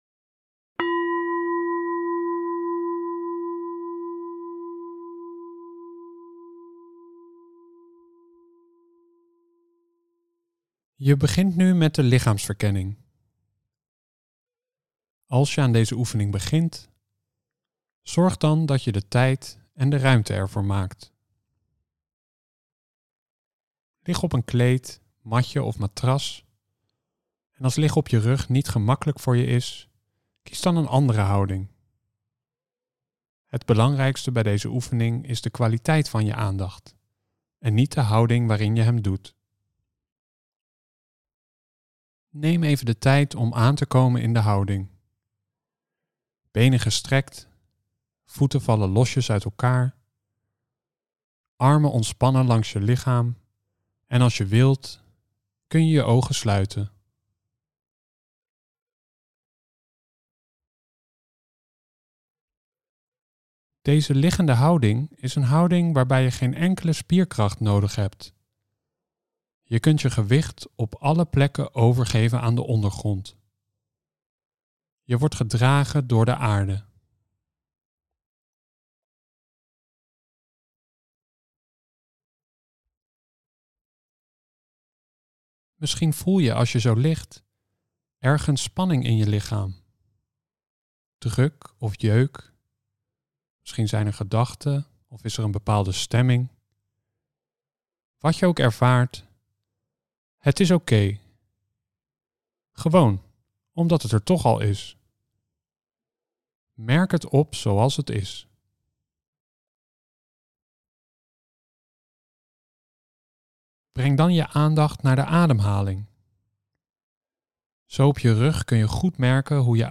De lichaamsverkenning (bodyscan)